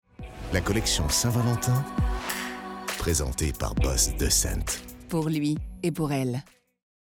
Voix off homme grave et sensuelle pour Hugo Boss The Scent
Sensuel, viril et charmeur.
Billboard pour Disney + et le parfum The Scent de Hugo Boss.
Ici un parfum, j’ai placé ma voix pour bien faire ressortir le timbre et j’ai utilisé une hauteur de voix assez basse.
La diction est légère et mais affirmée pour gagner en sensualité et virilité.